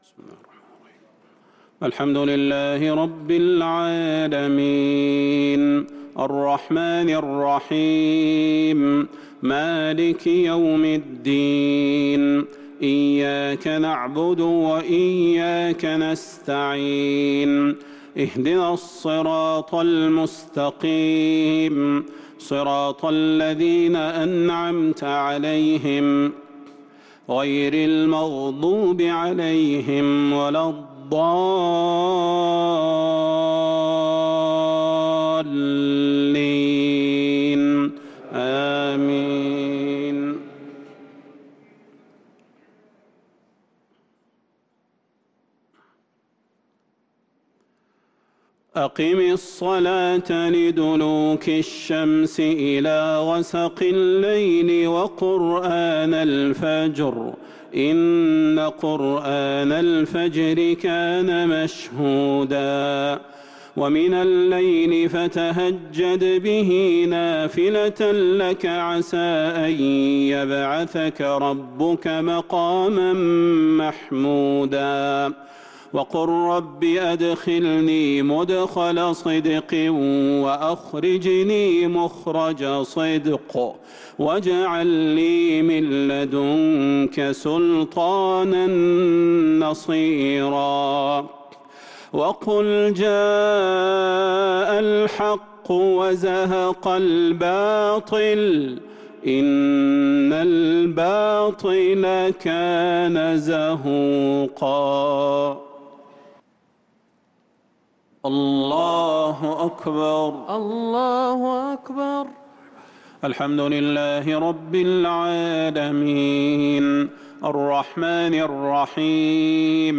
صلاة العشاء للقارئ صلاح البدير 17 رمضان 1443 هـ
تِلَاوَات الْحَرَمَيْن .